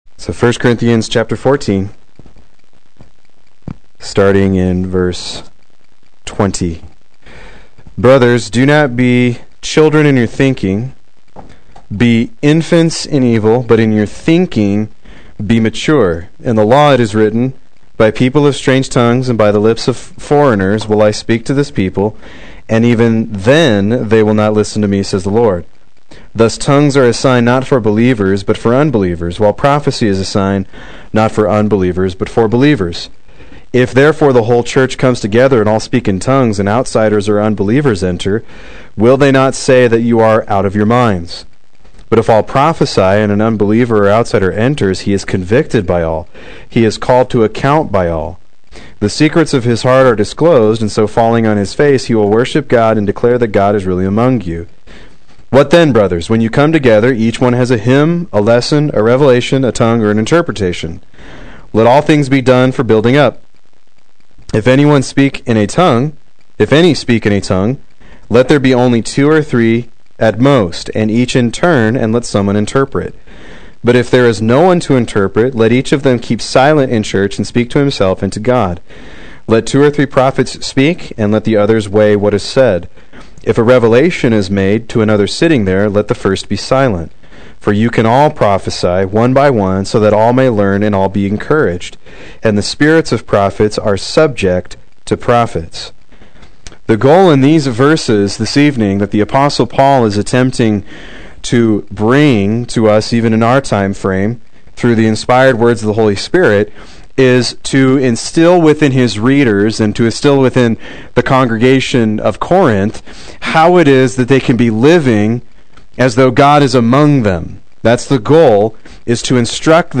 Proclaim Youth Ministry - 10/07/16
Play Sermon Get HCF Teaching Automatically.